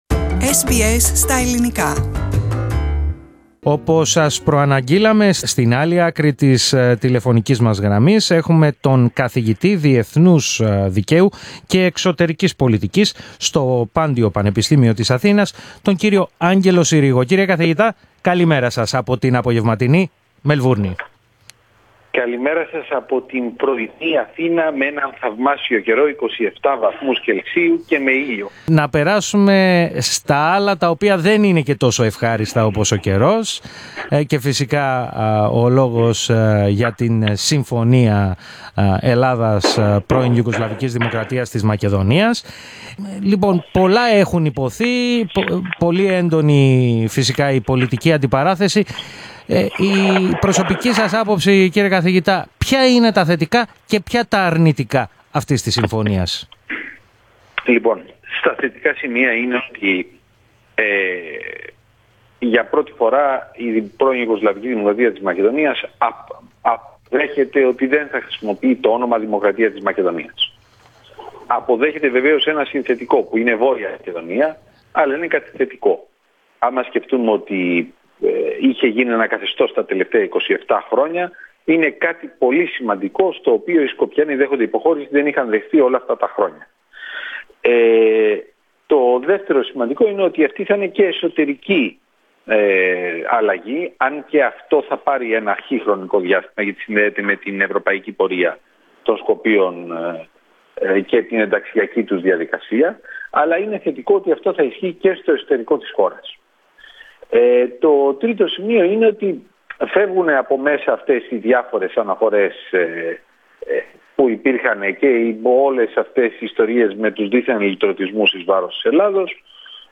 Ήταν κακή η διαπραγμάτευση, που οδήγησε στην συμφωνία Ελλάδας πΓΔΜ. Τάδε έφη, ο Αναπληρωτής Καθηγητής, Διεθνούς Δικαίου και Εξωτερικής Πολιτικής, στο Πάντειο Πανεπιστήμιο της Αθήνας, Άγγελος Συρίγος, σε συνέντευξη που παραχώρησε στο πρόγραμμα μας.